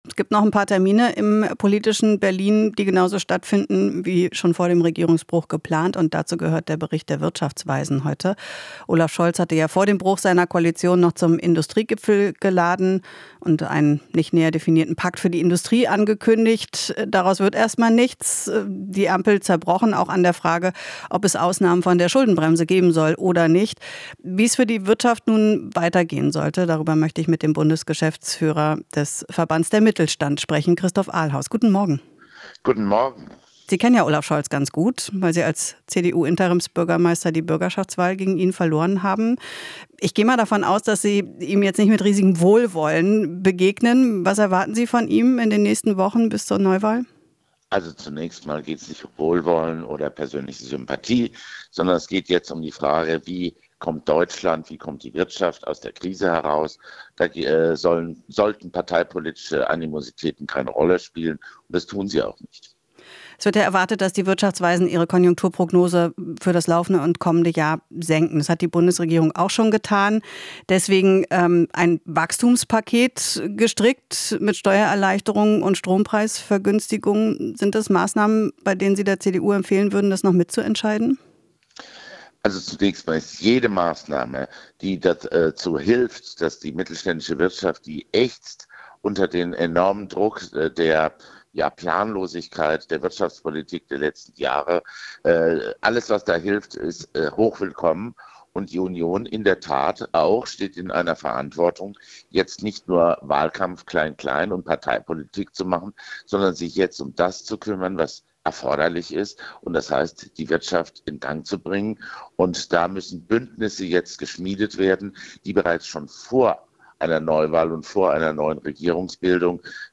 Interview - Mittelstand fordert Verantwortungs-Bündnis statt Parteitaktik